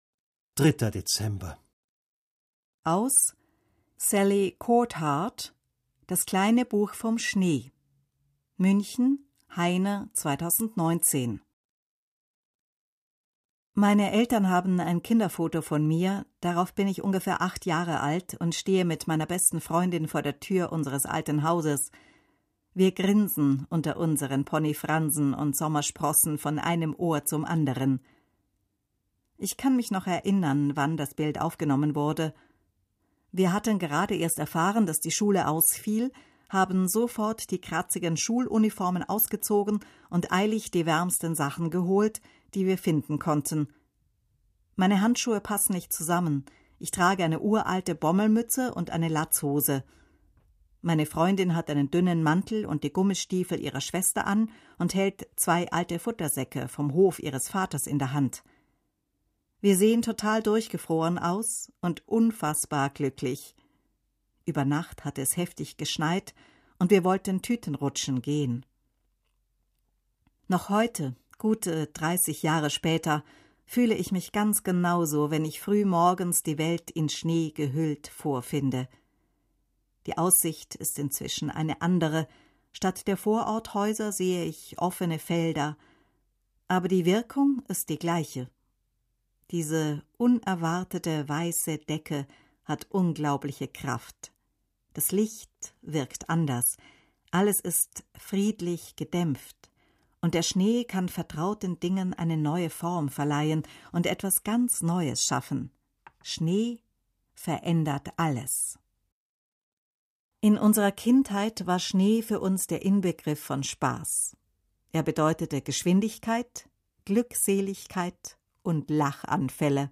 Akustischer Adventkalender © BSVÖ